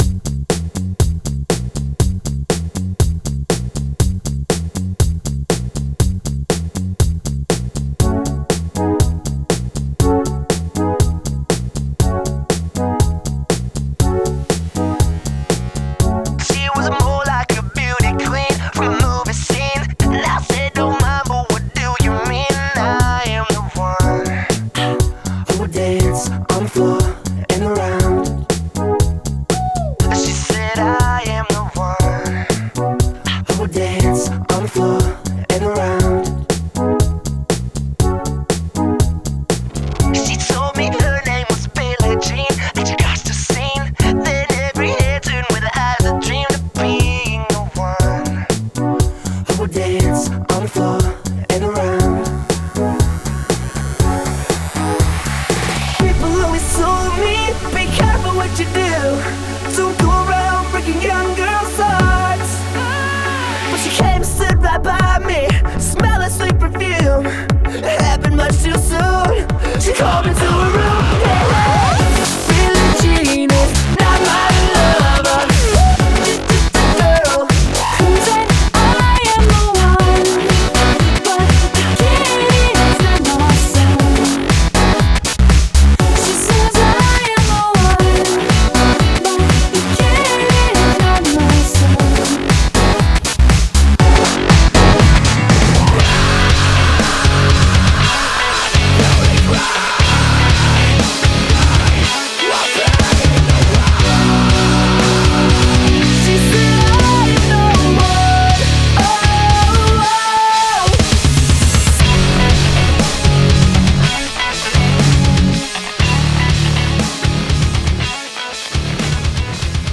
BPM60-120